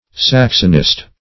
Saxonist \Sax"on*ist\, n. One versed in the Saxon language.